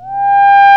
FUNK SYNTH 2.wav